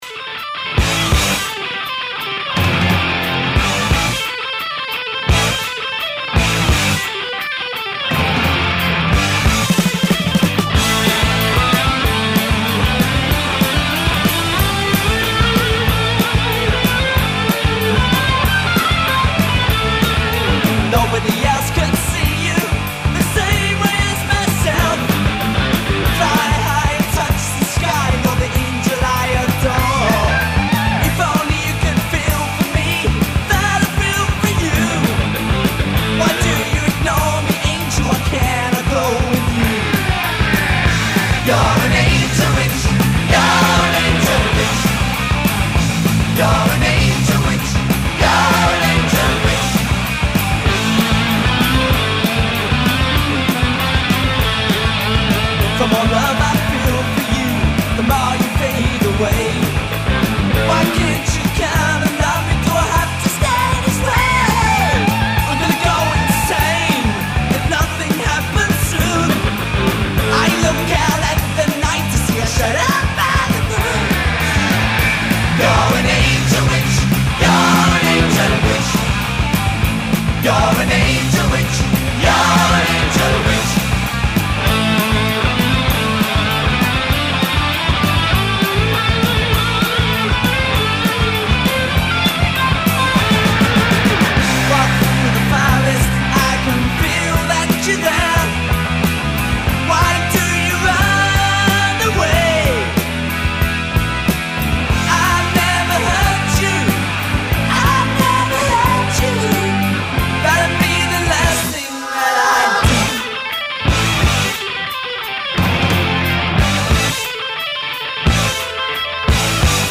la venue de 2 invités vétérans